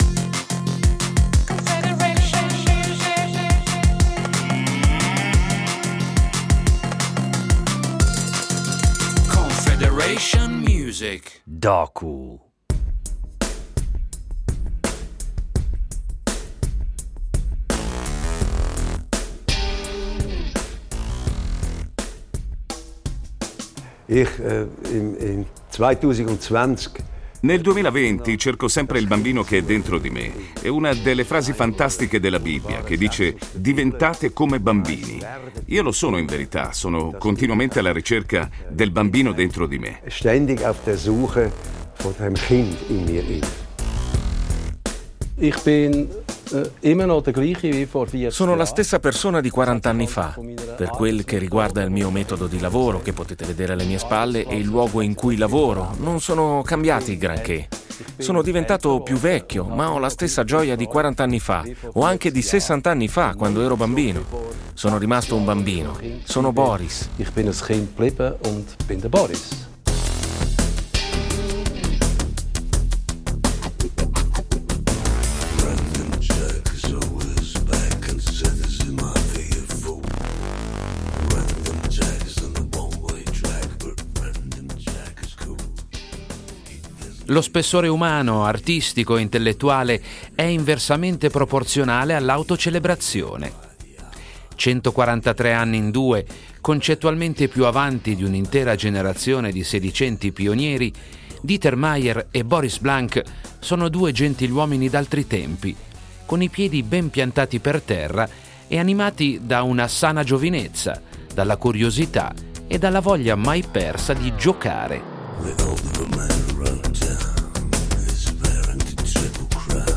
Li abbiamo incontrati in occasione della pubblicazione di "Point" l’ultimo album degli Yello , uscito il 28 agosto del 2020, 4 decenni esatti dopo il debutto del primo “Solid pleasure”. Ci immergiamo in un verde così fitto che non sembra neppure di essere a Zurigo, sotto il porticato della stupenda villa zurighese di Dieter Meier , dove gli Yello hanno lo studio di produzione.